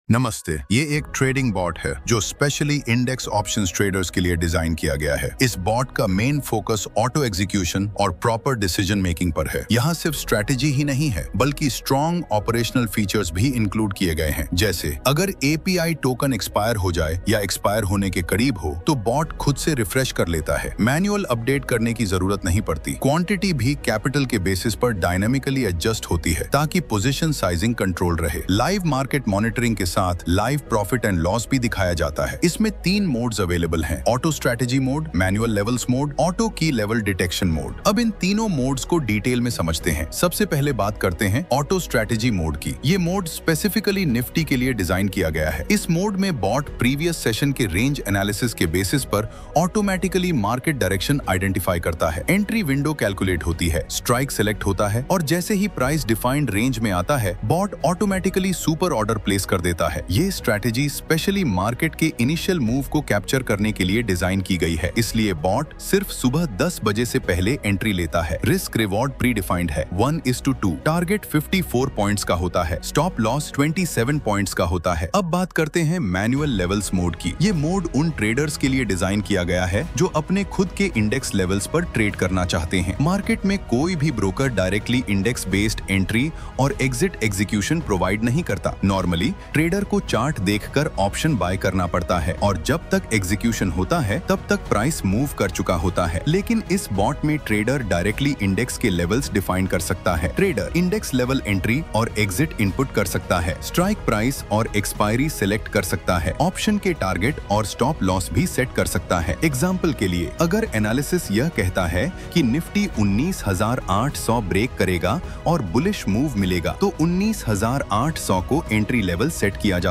AIVOICE.mp3